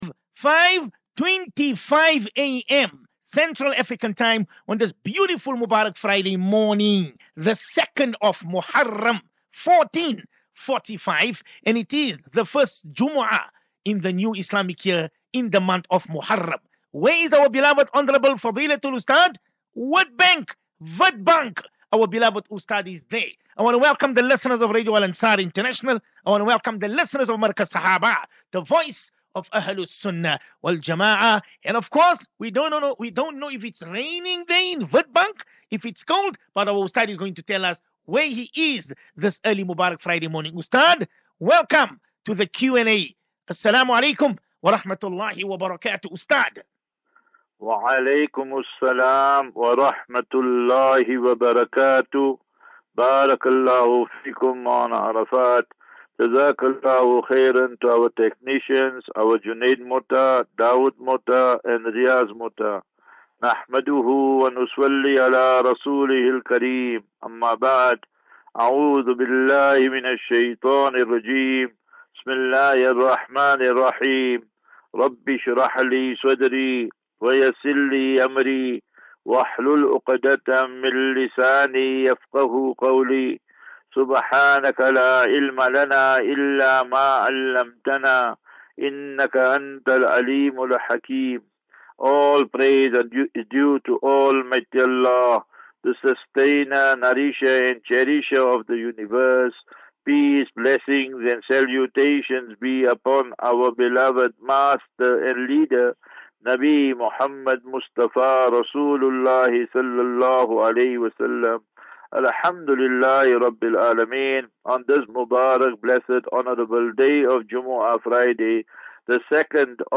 As Safinatu Ilal Jannah Naseeha and Q and A 21 Jul 21 July 2023.